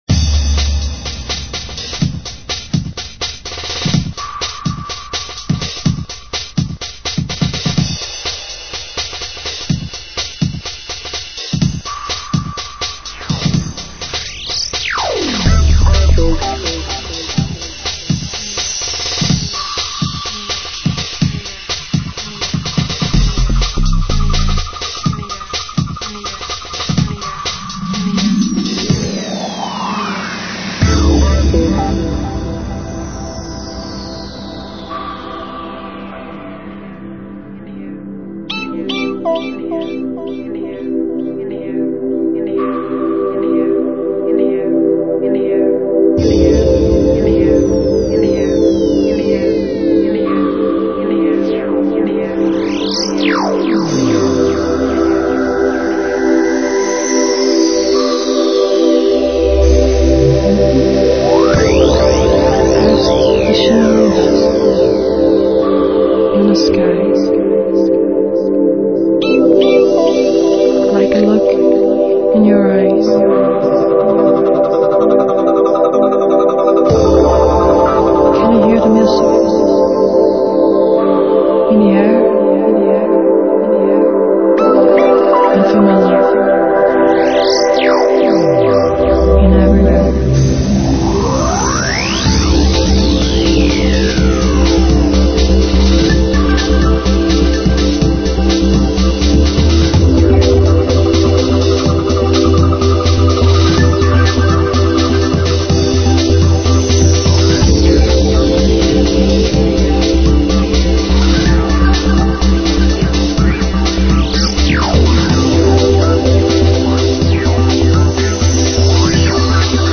dance/electronic
Trippy and Trancy with break beats.
House